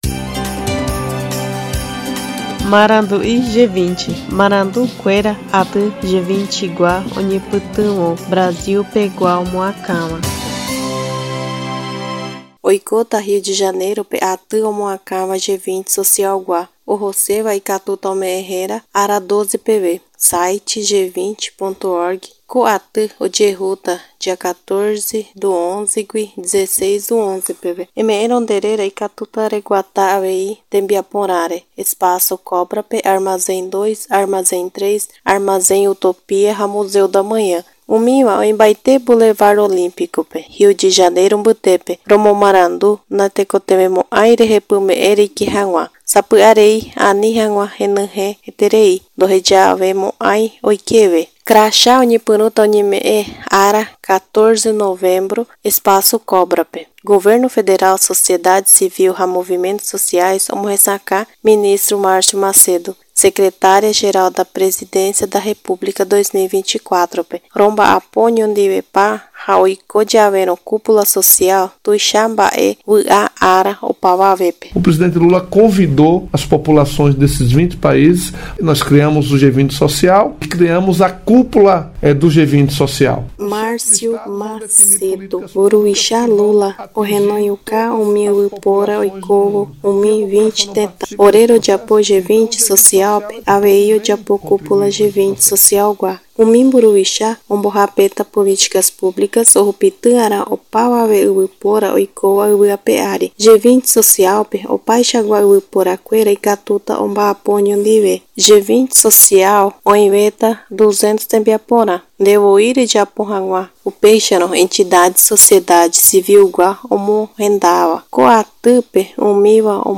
A reunião do Grupo de Educação do G20 abordou temas finais da agenda brasileira, focando no engajamento escola-comunidade. Ouça a reportagem e saiba mais.